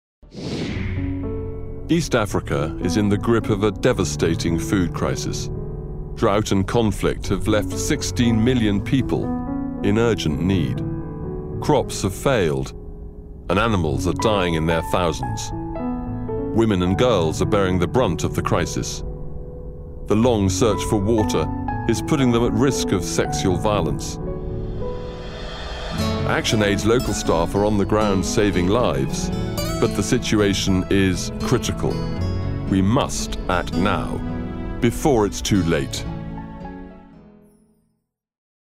Captivate Your Audience with a Resonant British voice
CHARITY APPEAL